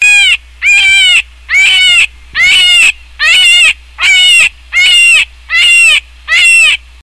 Громкий вопль зайца